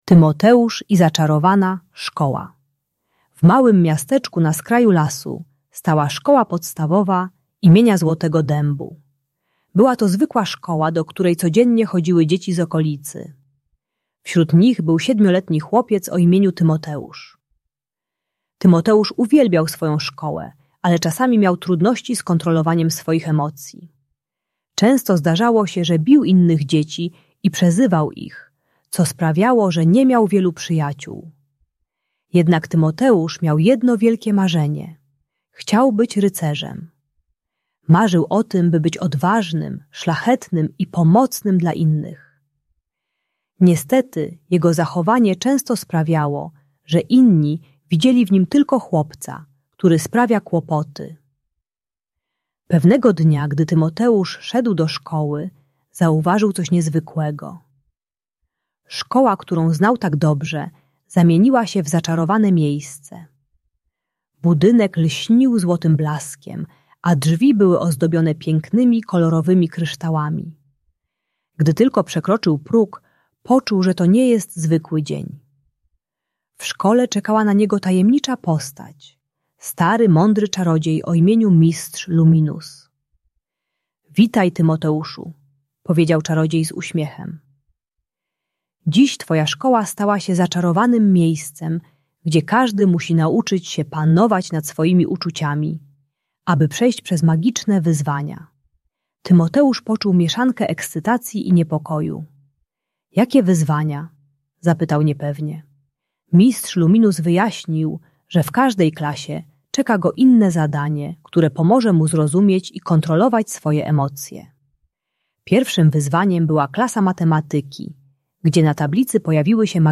Tymoteusz i Zaczarowana Szkoła - Bunt i wybuchy złości | Audiobajka